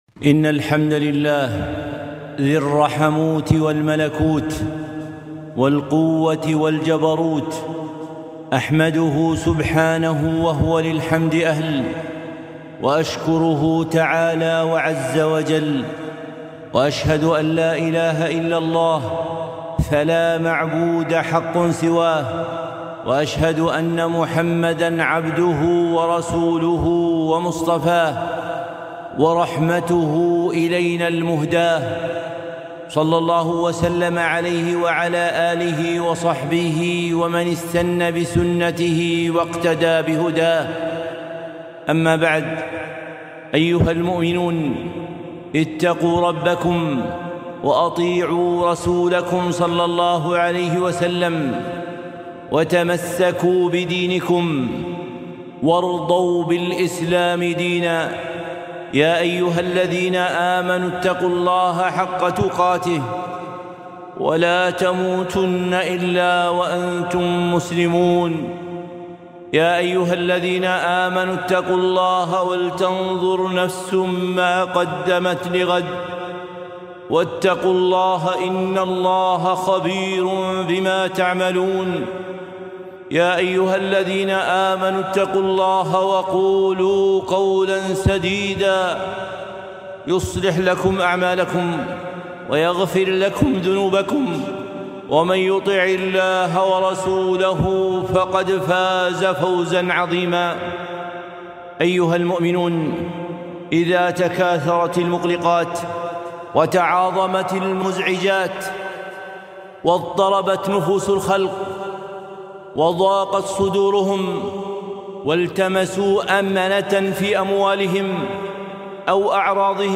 خطبة - الفرار إلى الله